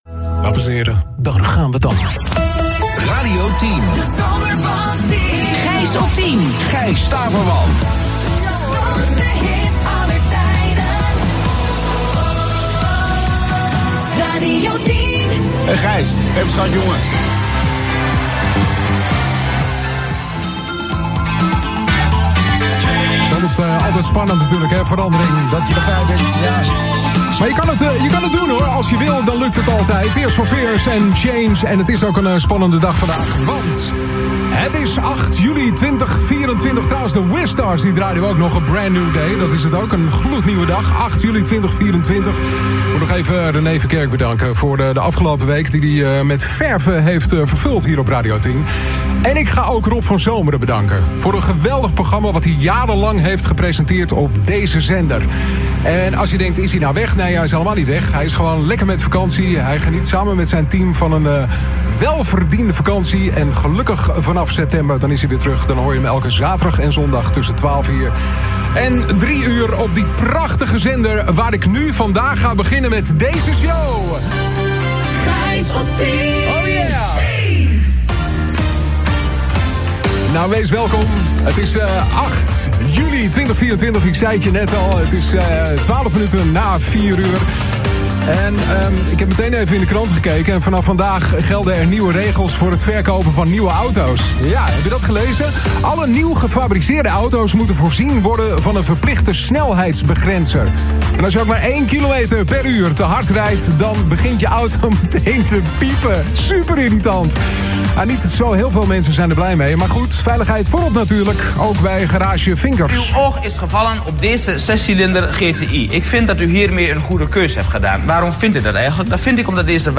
Het is een spannende dag”, bekende Gijs Staverman aan het begin van zijn eerste programma op Radio 10. Voortaan is de deejay elke werkdag tussen 16 en 19 uur te horen met ‘Gijs op 10’.